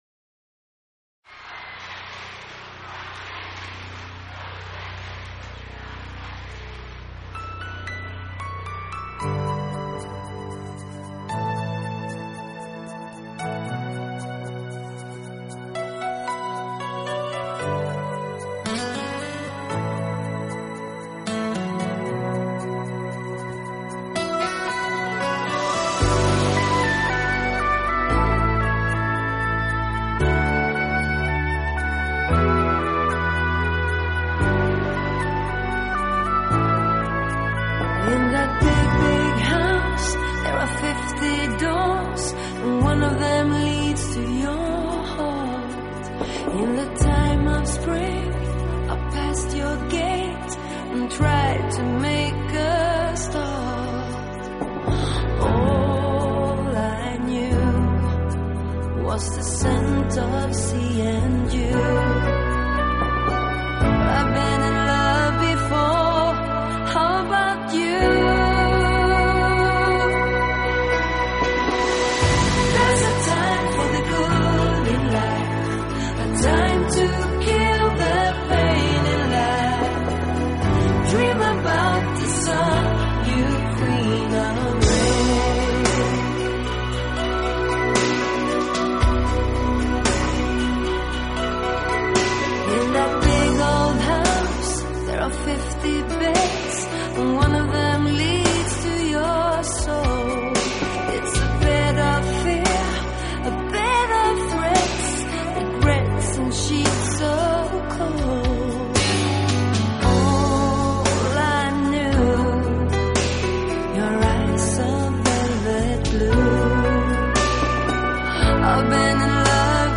欧美金曲